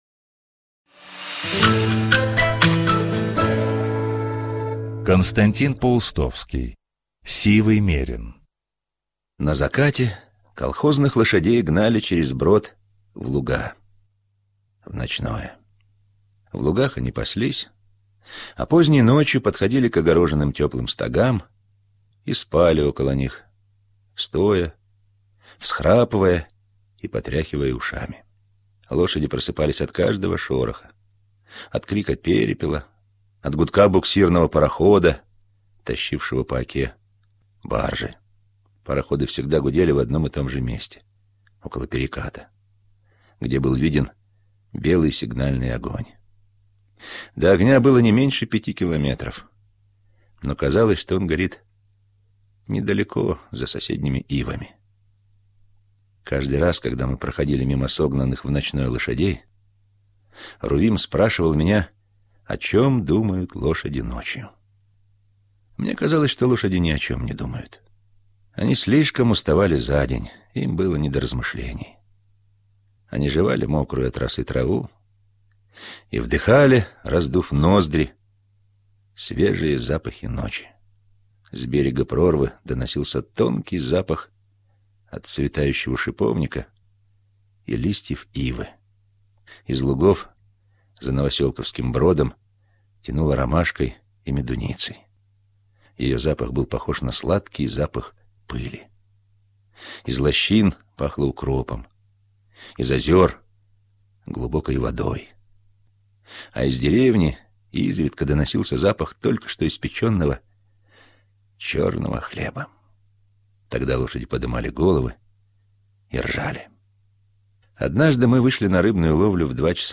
Сивый мерин - аудио рассказ Паустовского К. Однажды, идя на ночную рыбалку, за автором и Рувимом увязался старый мерин.